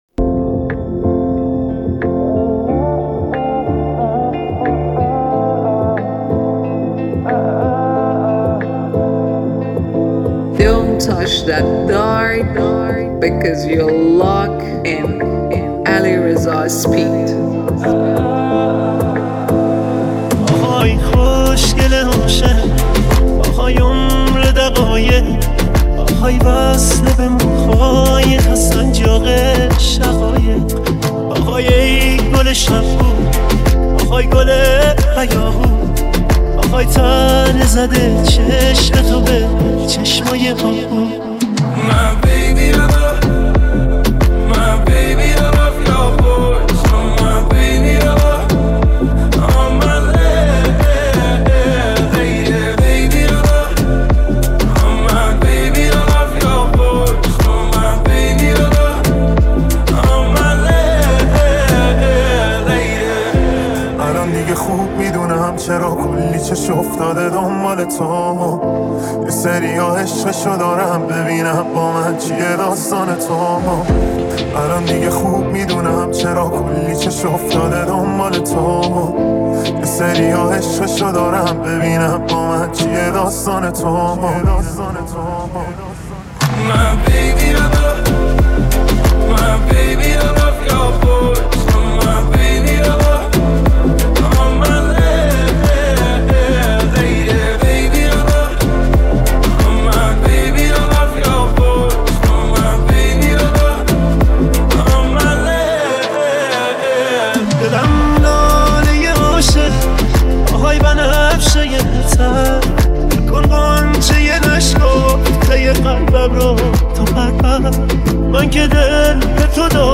ریمیکس جدید